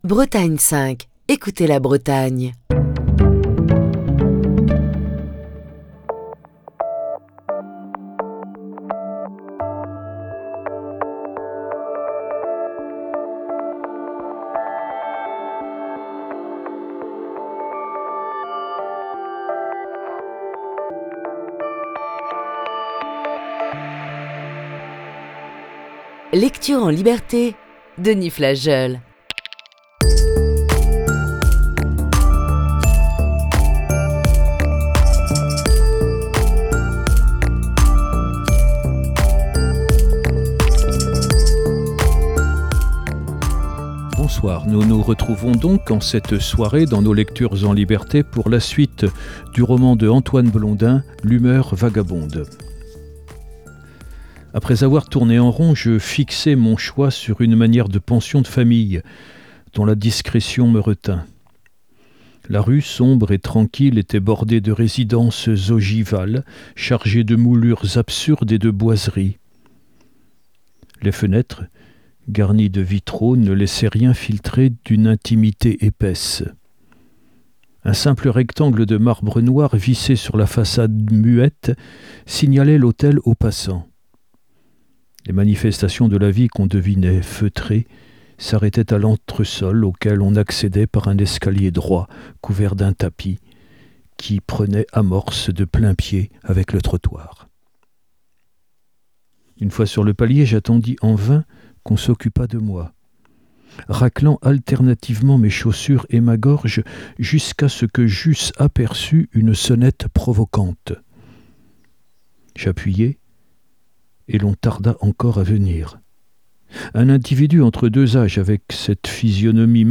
lit le roman d'Antoine Blondin "L'humeur vagabonde". Voici ce mercredi la troisième partie de ce récit.